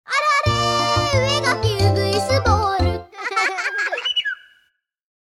waraigoe.mp3